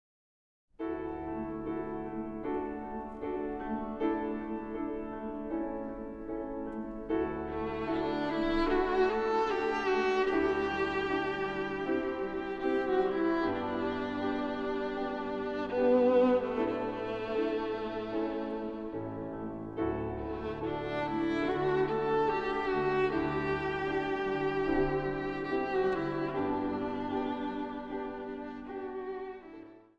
Zang | Gemengd koor